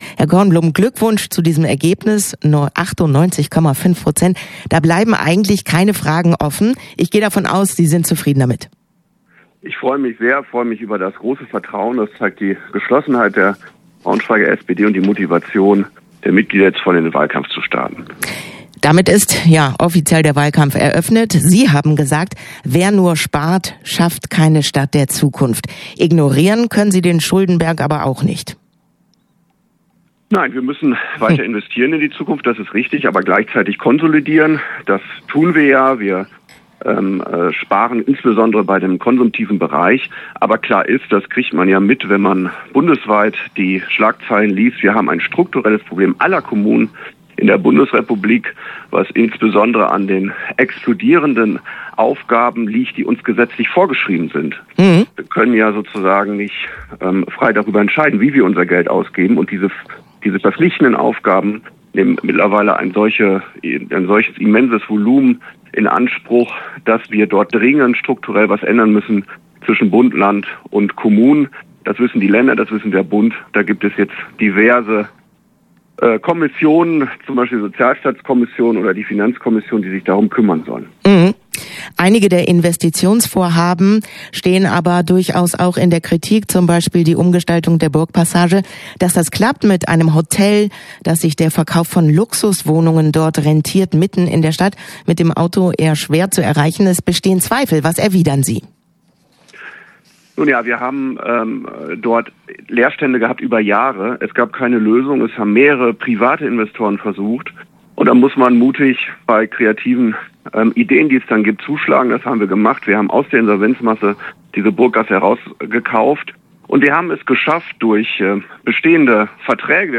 Interview-OB-Kandidat-Kornblum_nb.mp3